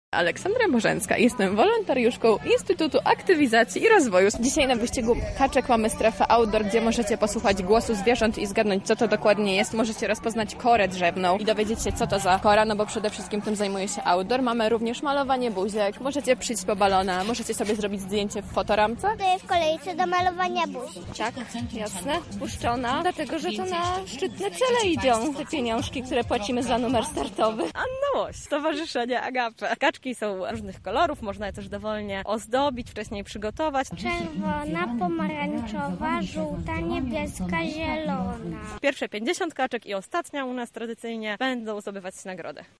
Razem z nimi była nasza reporterka: